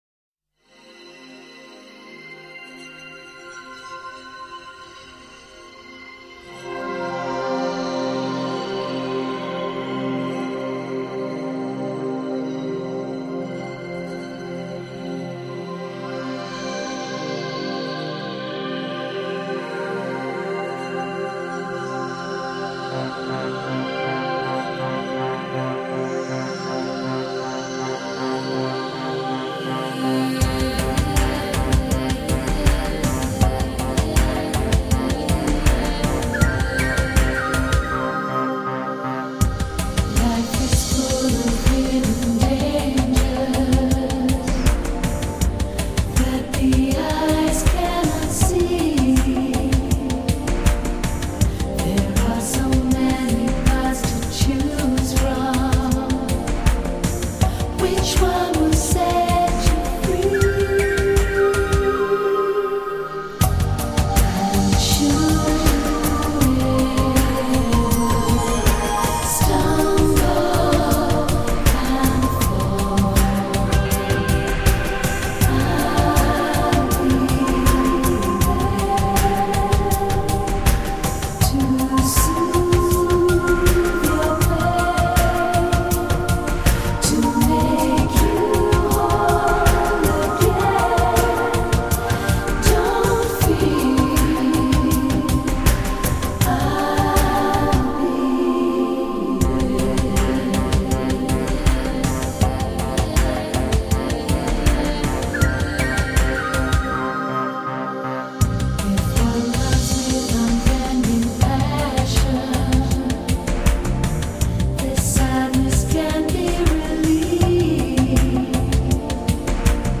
音乐类别: Ethnic, World Music